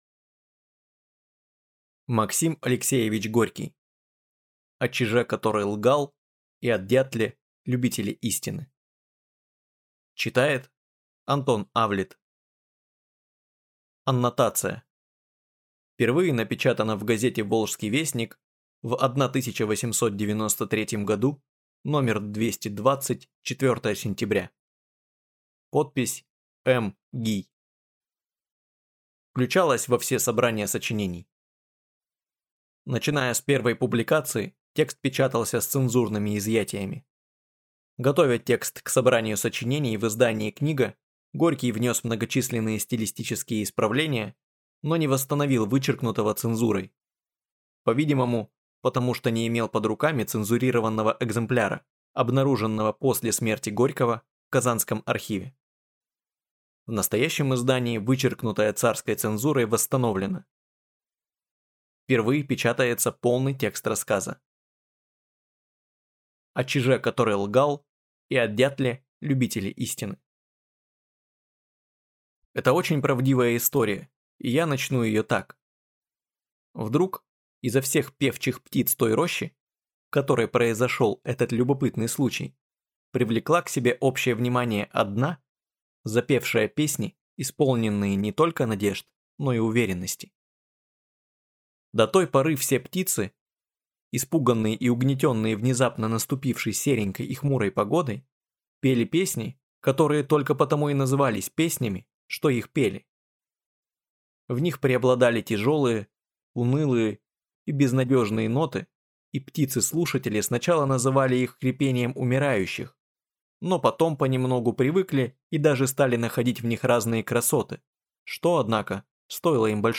Аудиокнига О чиже, который лгал, и о дятле – любителе истины | Библиотека аудиокниг